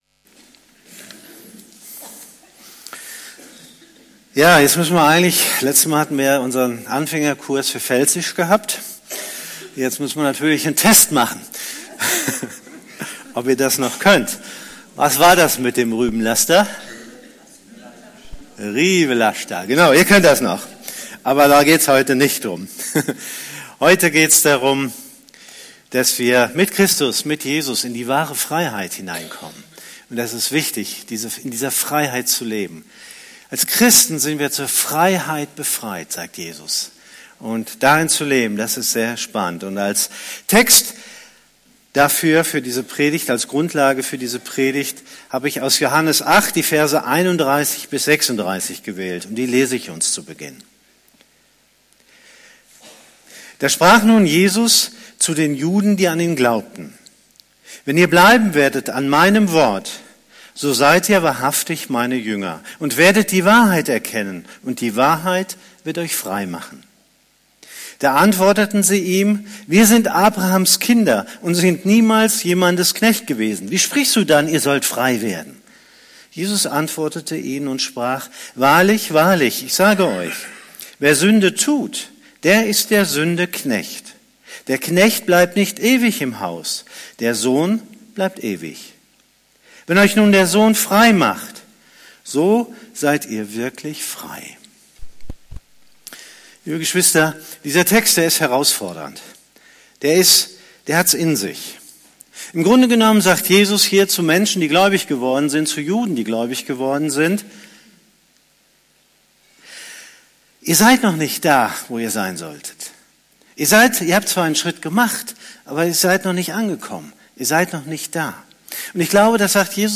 Predigt
predigt.mp3